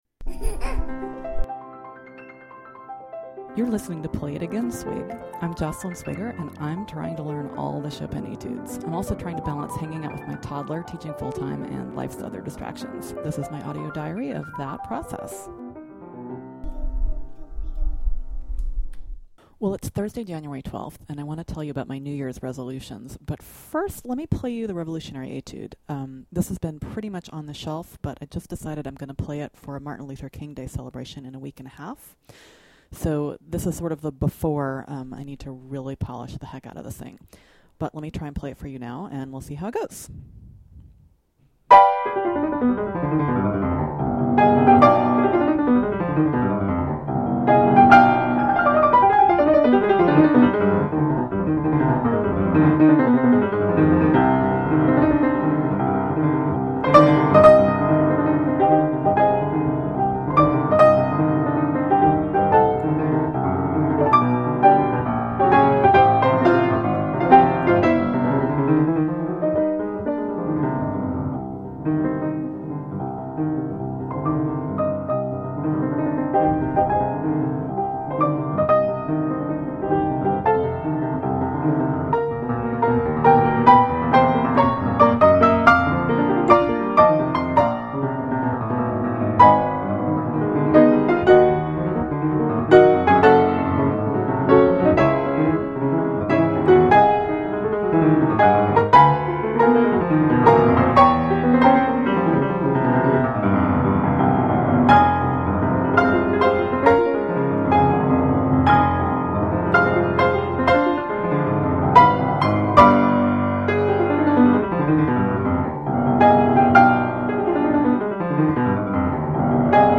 a sloppy version
ii-V-I patterns